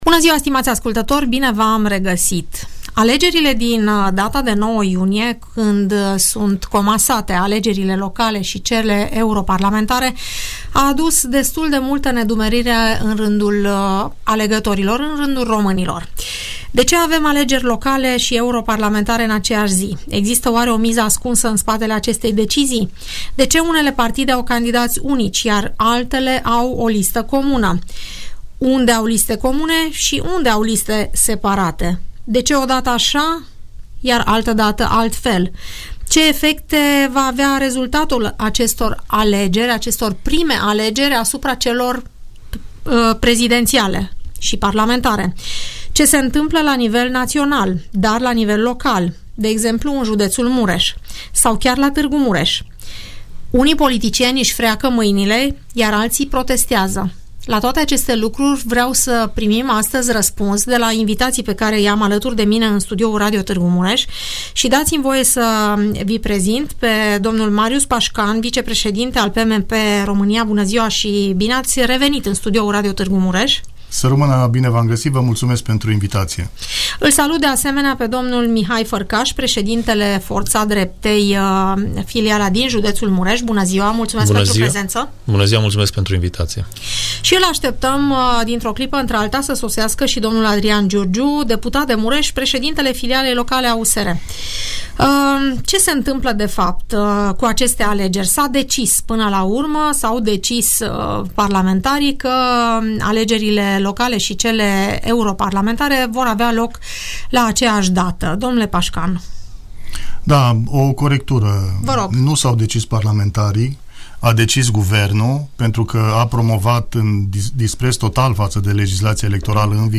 Reprezentanții Alianței Drepta Unită din județul Mureș vorbesc la Radio Tg. Mureș despre felul în care sunt organizate alegerile locale și europarlamentare, despre intențiile de a candida pe liste comune cât și despre miza celor 4 tipuri de alegeri din acest an.